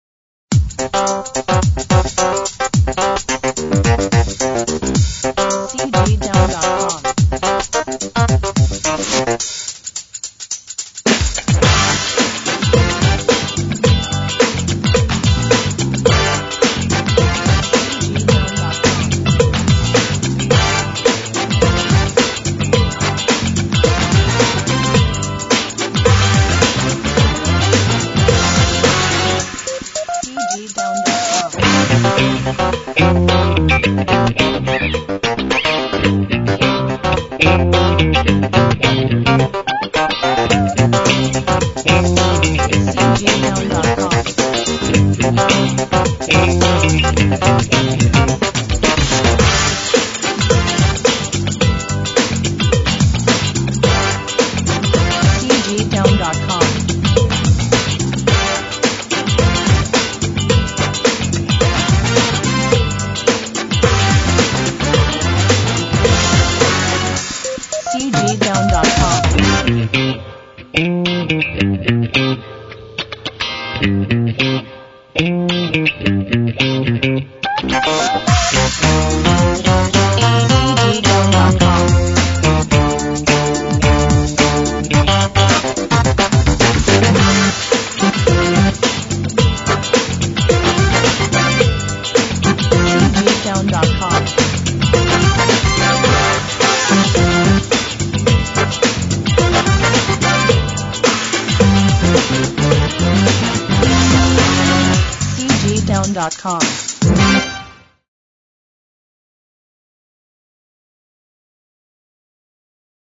时尚动感